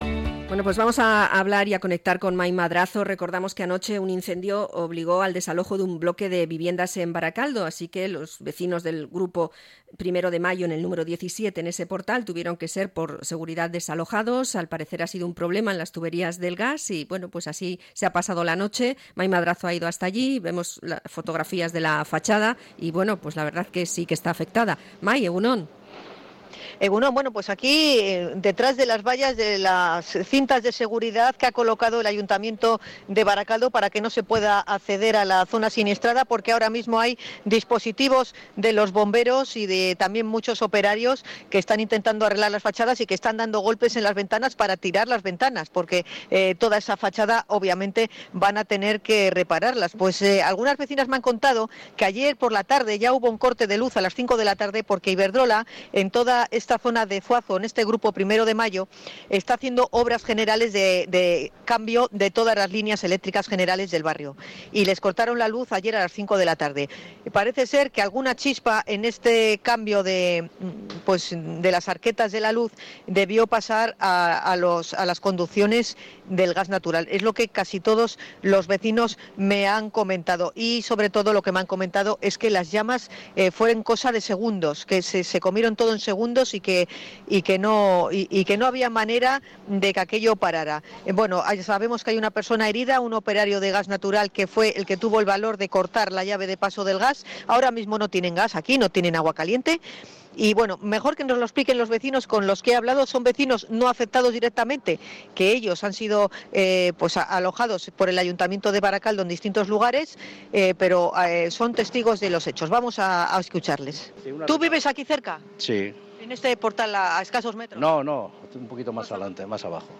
Hemos hablado con vecinos de la zona testigos del fuego